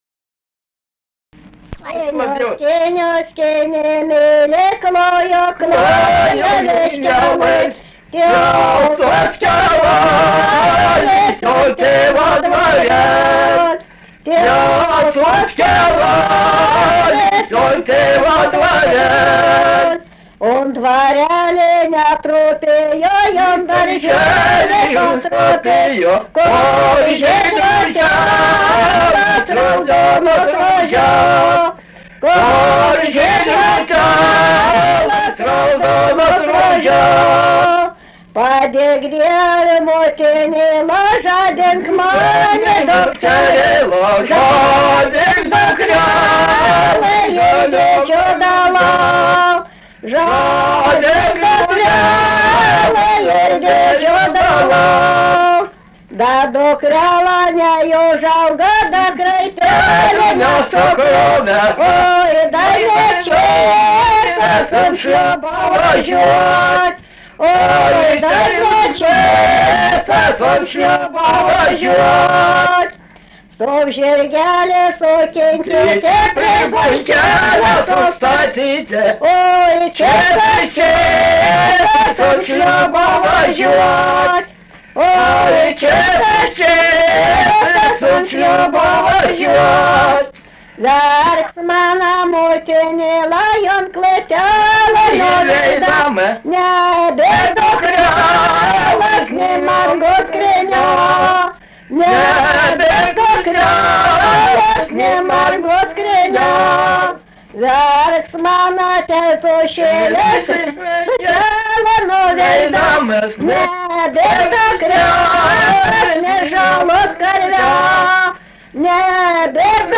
vaišių daina
Atlikimo pubūdis vokalinis